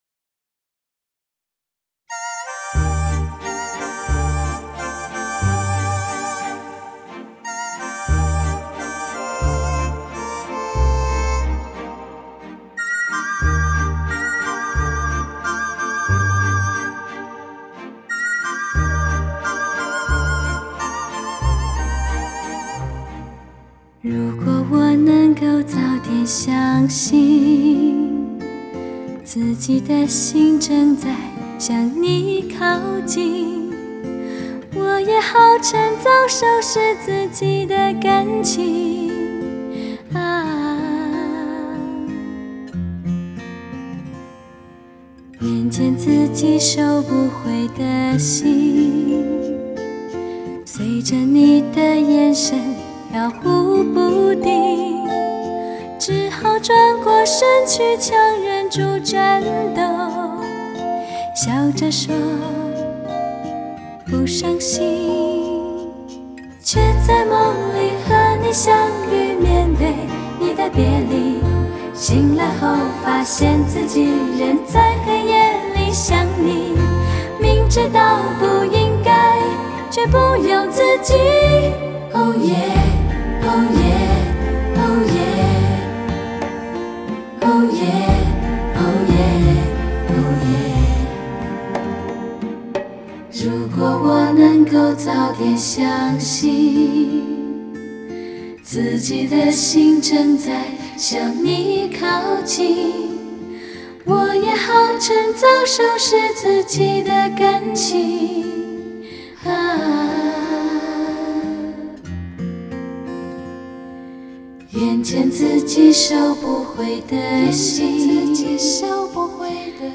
人声润厚通透 情感洋溢  质感层次感一一再现眼前